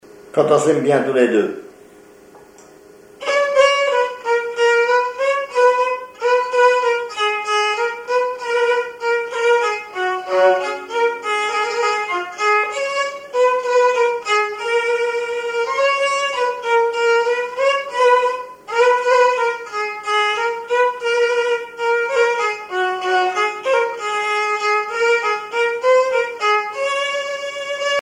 violoneux, violon
danse : valse musette
Pièce musicale inédite